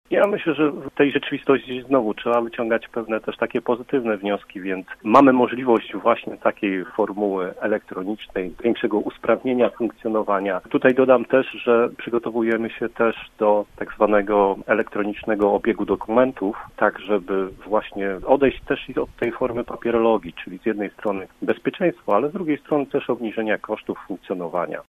Zdalne obrady radnych umożliwiają przepisy, które weszły w ramach tarczy antykryzysowej – wyjaśniał w Rozmowie Punkt 9 przewodniczący zielonogórskiej Rady Miasta.
Nowa sytuacja spowodowała też, że radni zmienią sposób pracy – dodawał Piotr Barczak: